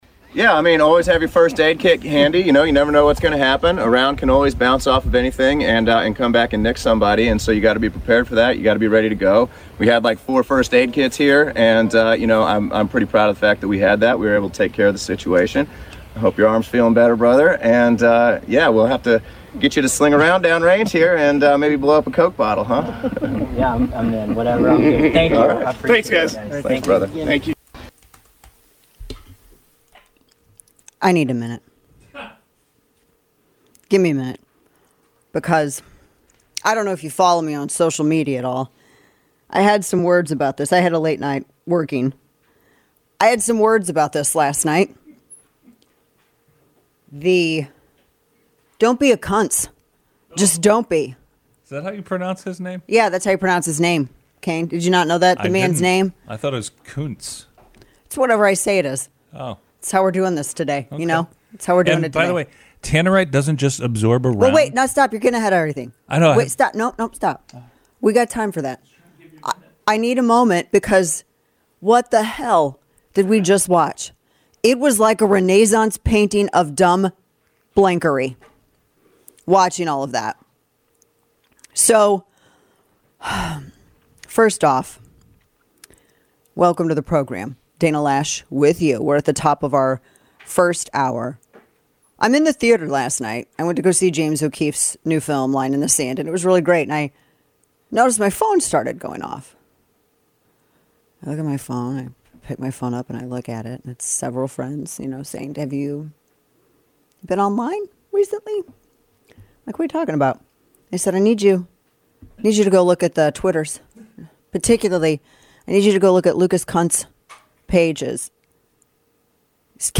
Dana breaks down the proposed constitutional amendments on Florida's ballot. Florida Gov. Ron DeSantis joined Dana Loesch to explain Amendment 3 and Amendment 4, two referendums on the Florida ballot that would eliminate parental rights on abortion decisions and prevent competition in the cannabis industry.